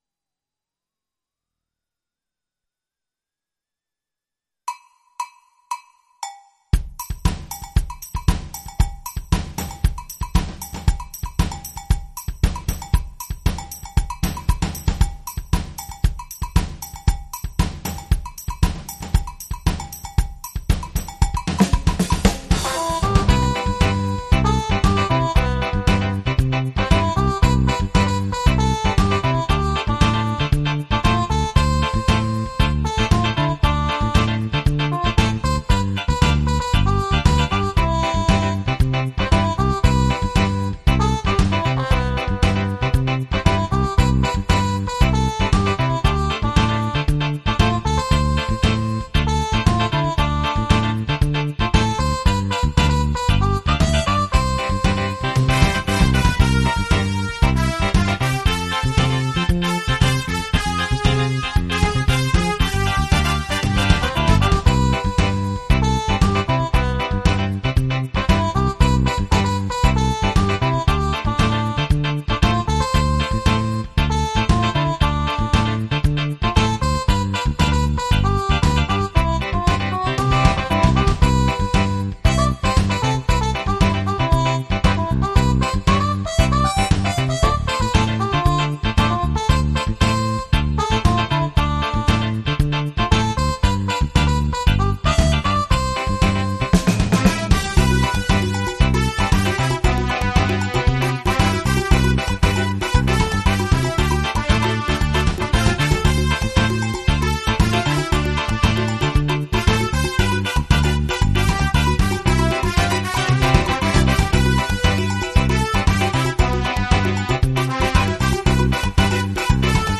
versione strumentale multitraccia